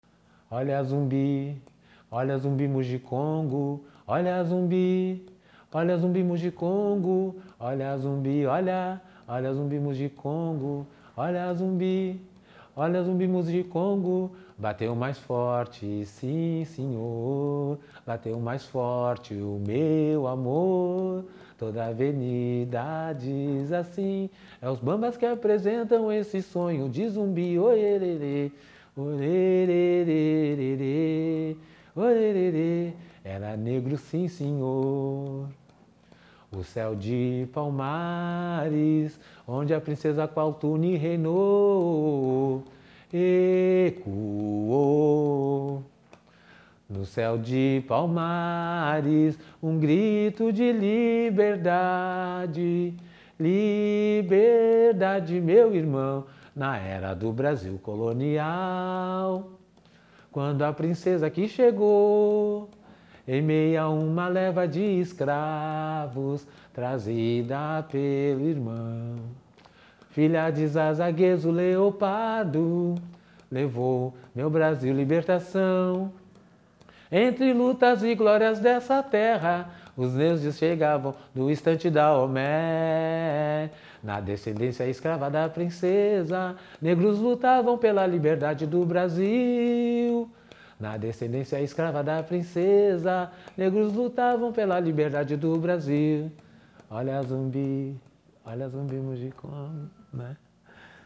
Gênero: Samba Enredo